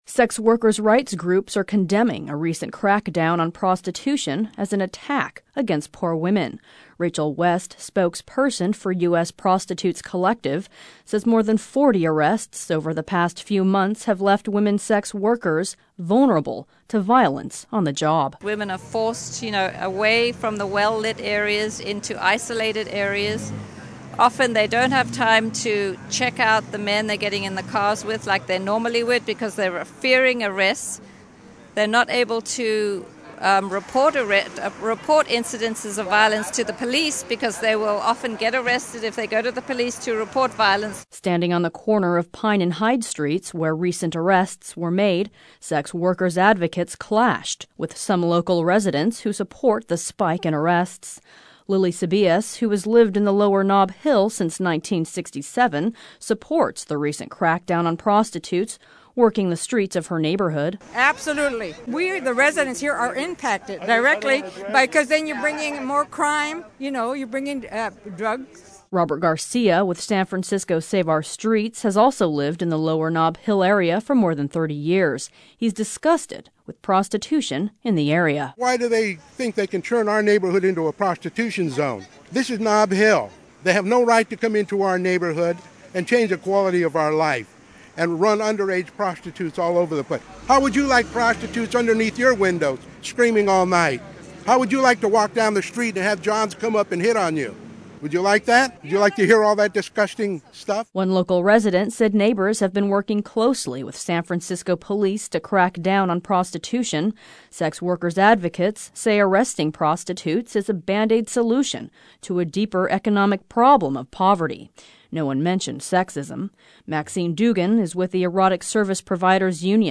Sex Workers Rally in San Francisco
Standing on the corner of Pine and Hyde Streets, where recent arrests were made, sex workers' advocates clashed with some local residents who support the spike in arrests.